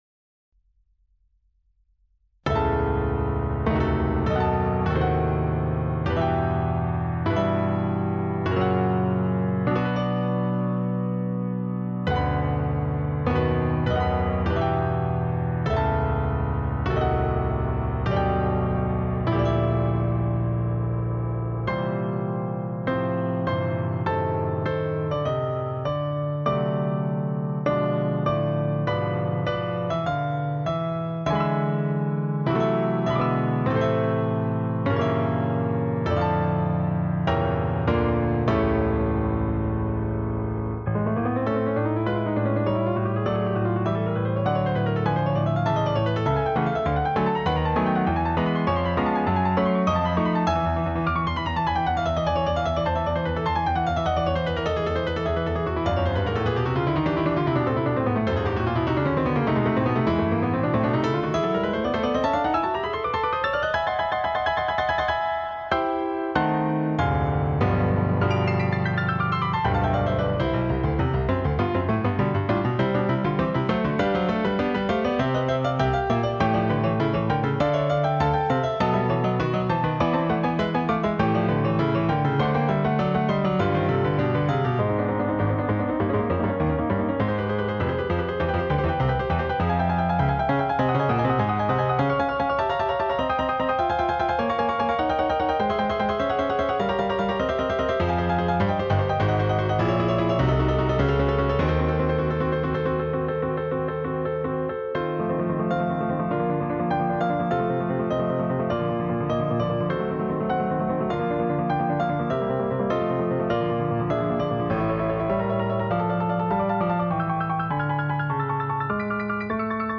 Czerny: Allegro Maestoso - The School of Fugue Playing Op.400 No.5